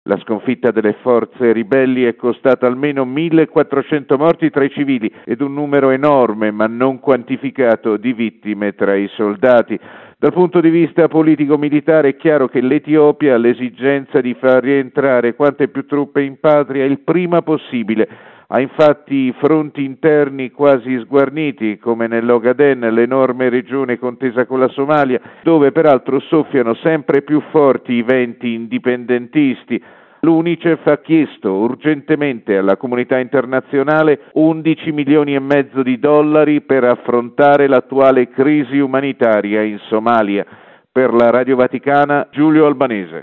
Preoccupazione sul piano umanitario per la sorte dei circa 400 mila profughi in fuga dalle violenze. Il servizio